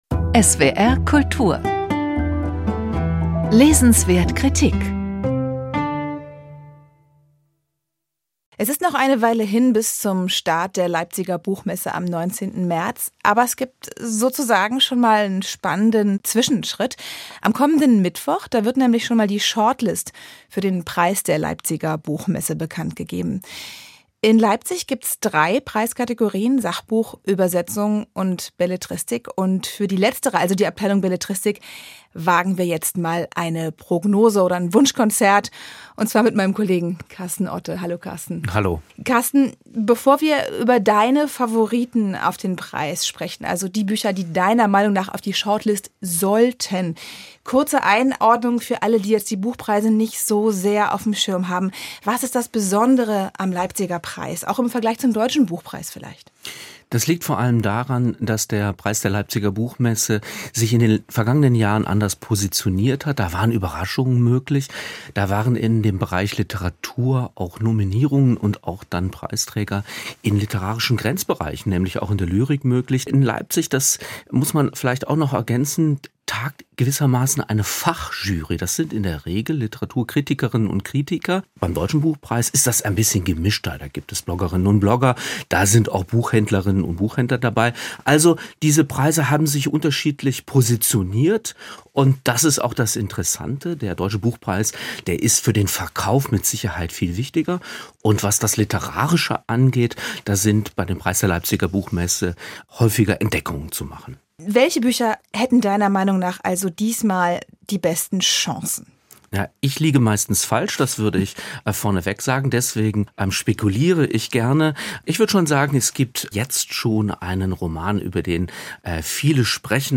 gespraech-zum-preis-leipziger-buchmesse-2026.mp3